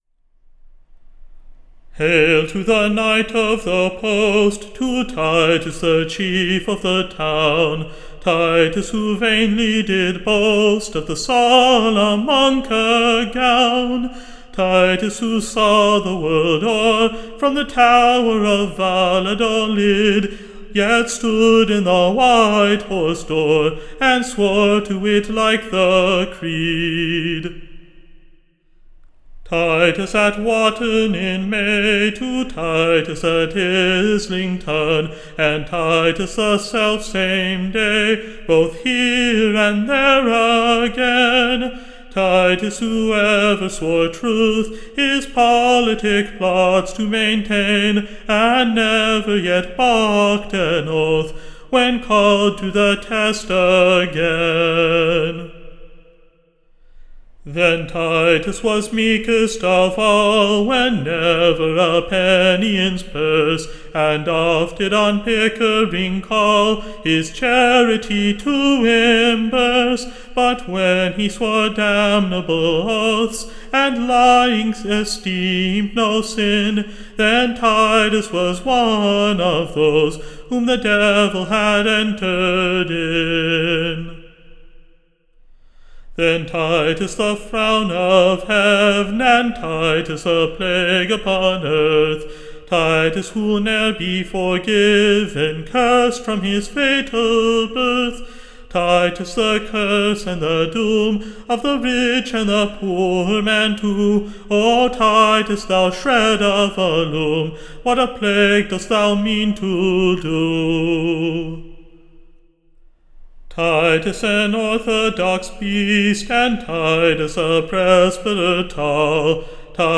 Recording Information Ballad Title Titus Tell-Troth: / OR, / The PLOT-FOUNDER Confounded. / A Pleasant New SONG.